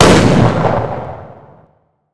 bigshotgun01.wav